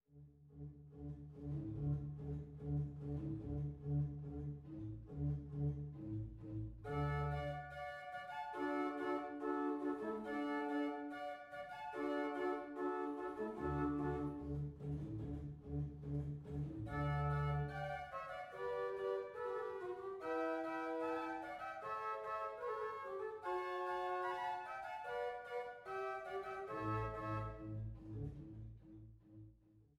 Orgel in Forchheim, Pfaffroda, Nassau und Ponitz